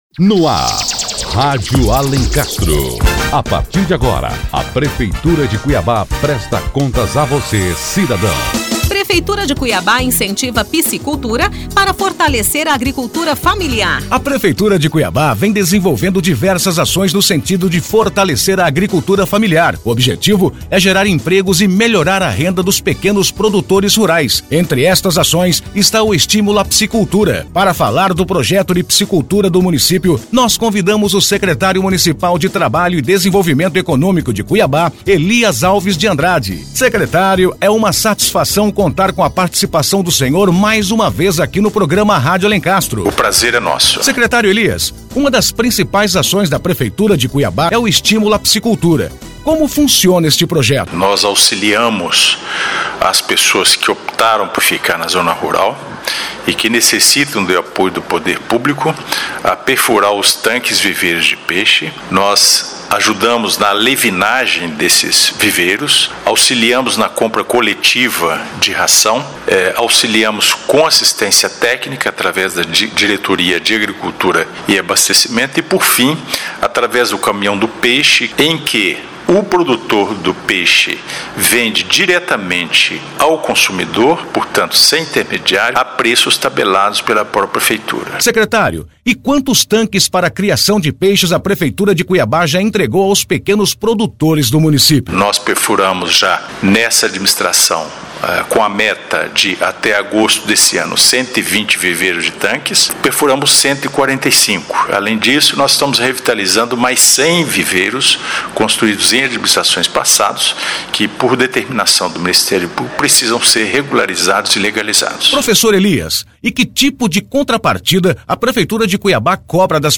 Notícias / 96º Programa 17 de Abril de 2014 17h49 Fortalecimento da agricultura familiar O Secretário Municipal de Trabalho e Desenvolvimento Econômico, Elias Alves de Andrade, fala sobre as diversas ações que a prefeitura está tomando no sentido de fortalecer a agricultura familiar. Entre estas ações, está o estímulo à piscicultura.